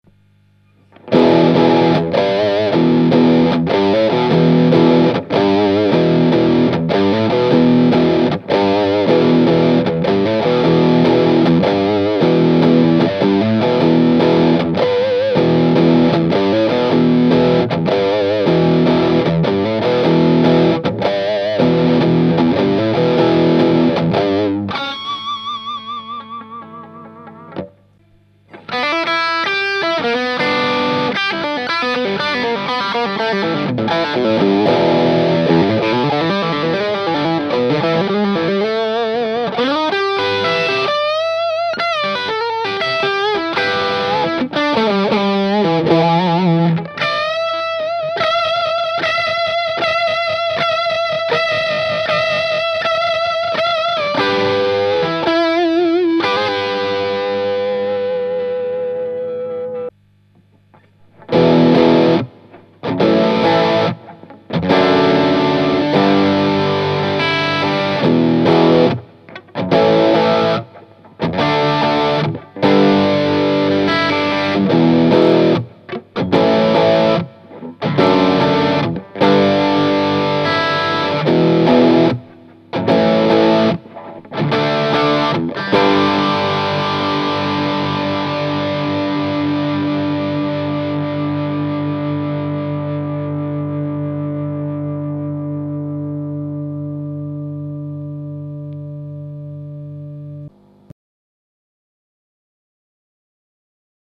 Voici une serie d'enregistrements qui ont été fait sur le Ten, l'ampli 10 watt de Pasqualiamps.
Le baffle utilisé est un "closed back" ave 2 HP Celestion G12-H. Les 3 premières plages ne contiennent aucun effet.
Plage 5 "Rock": Rythmique/Solo/Rythmique "
Réglages Tactile Custom: Micro manche simple (splité) Tone 100 % - Volume 100 %
Réglage Pasqualiamps Ten: Tone 5.5 - Volume 2
Réglages des effets: Tube Screamer (Drive: 2 Tone 2 Level 5)